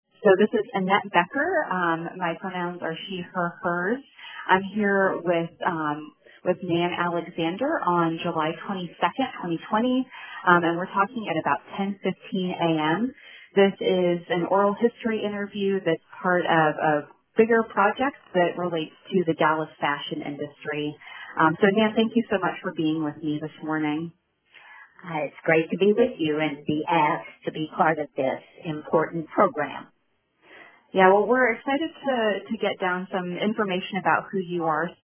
Introduction to interview